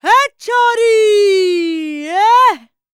VOICE 9C.wav